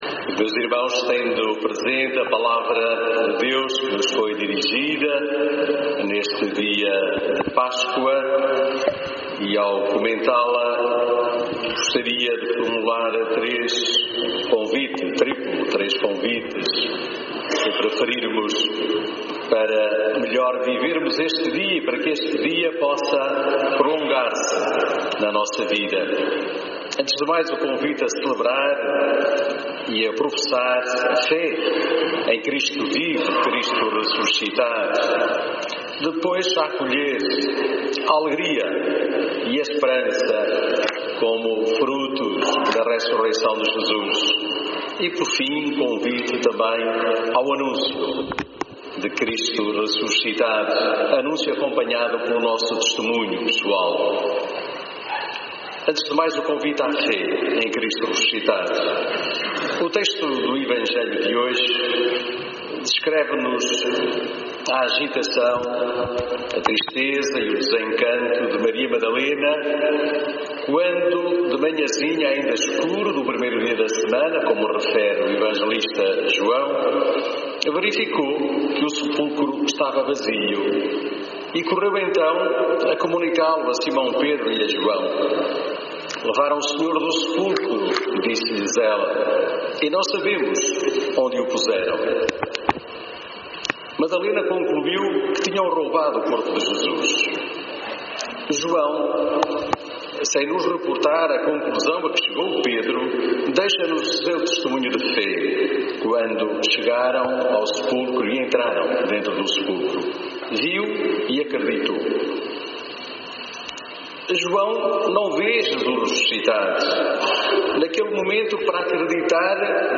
Homilia_domingo_pascoa_2018.mp3